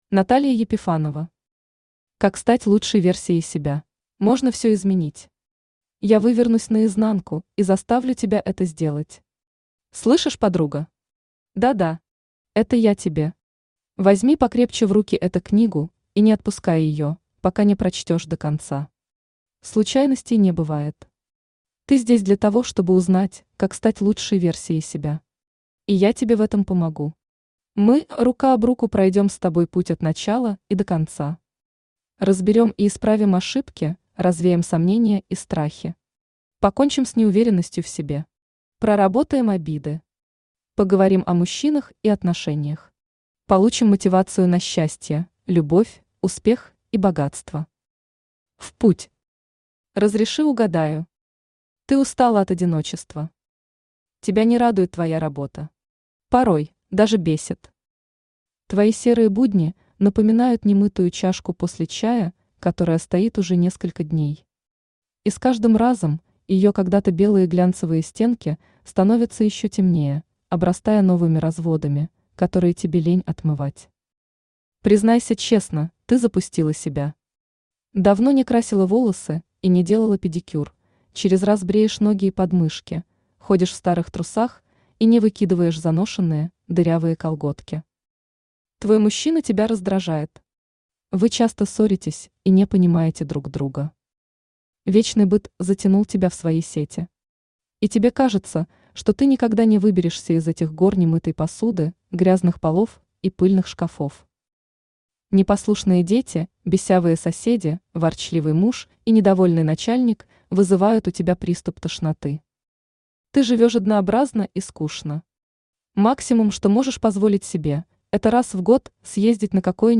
Аудиокнига Как стать лучшей версией себя | Библиотека аудиокниг
Aудиокнига Как стать лучшей версией себя Автор Наталья Епифанова Читает аудиокнигу Авточтец ЛитРес.